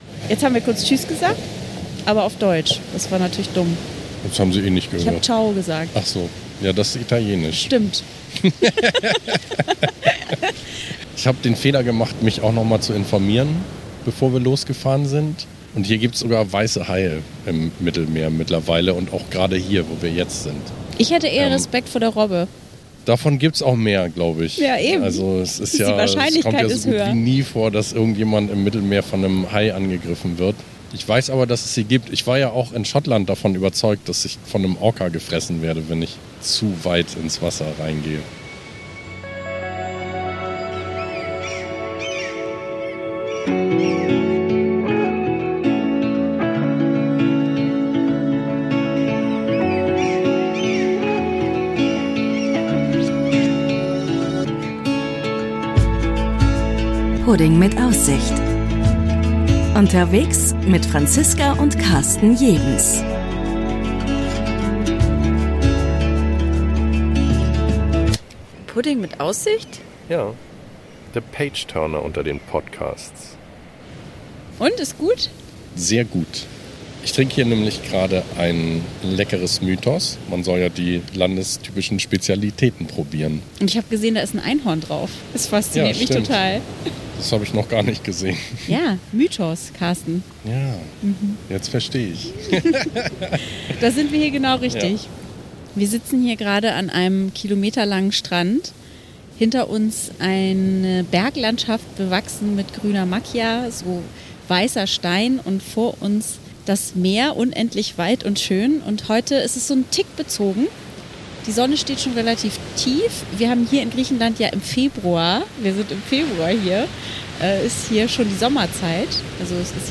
Ihr sitzt neben uns an einem traumhaften Strand auf einer griechischen Insel und hört die türkisblauen Wellen rauschen.